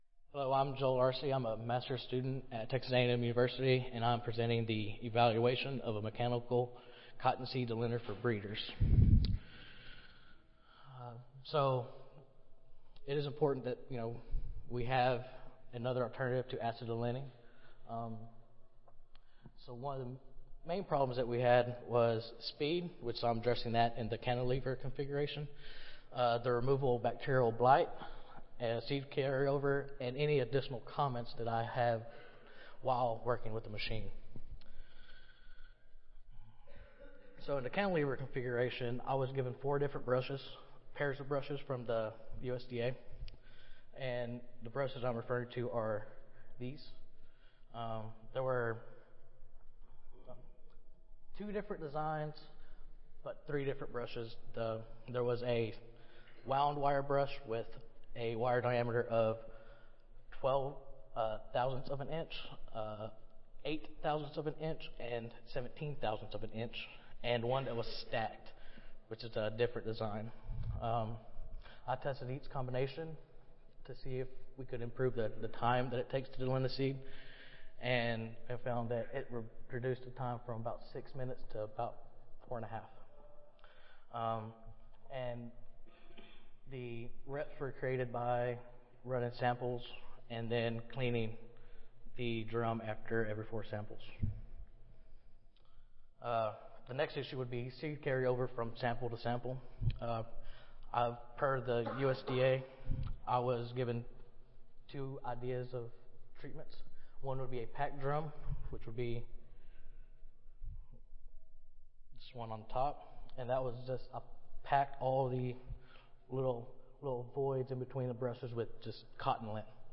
Audio File Recorded Presentation
See more of: Cotton Improvement - Lightning Talk Student Competition